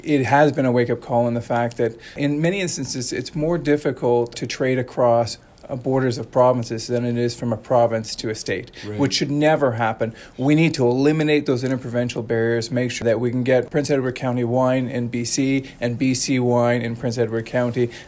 We chatted with Lawrence at his campaign office in Cobourg to get a sense of the main issues he is hearing at the door.